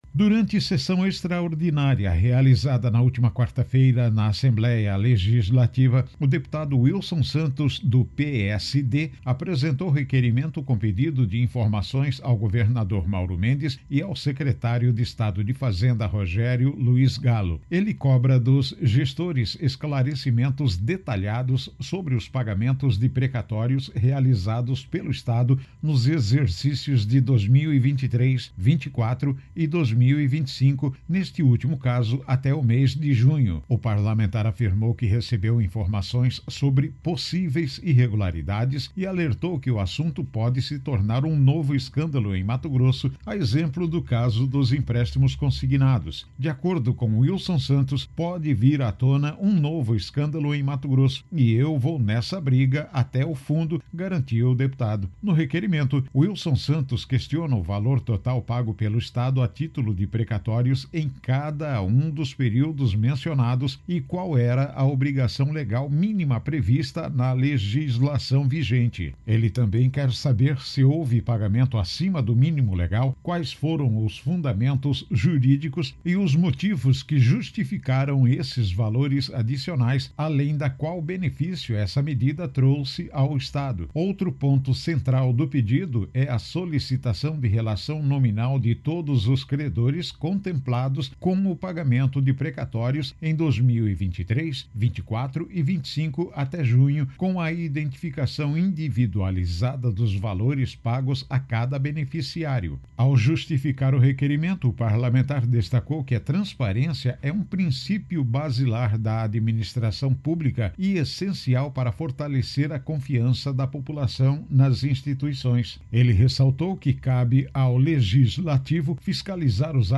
Voz: